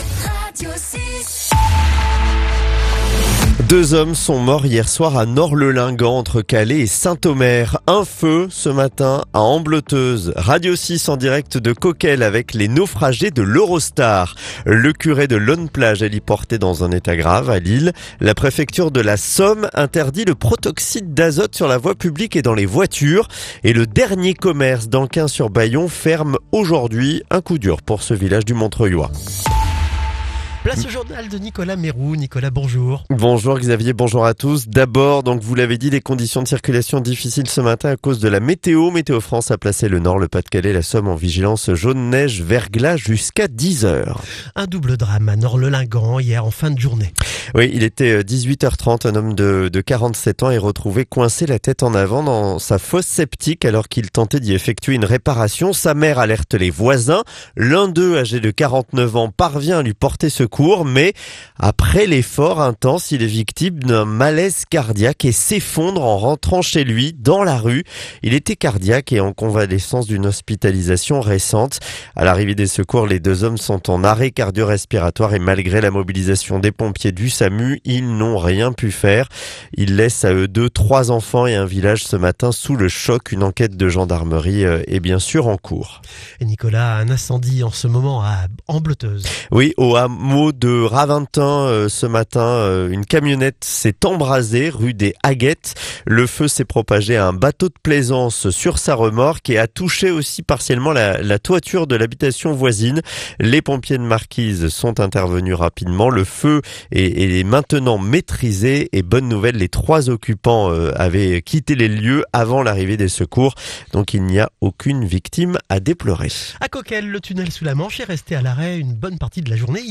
Le journal du mercredi 31 décembre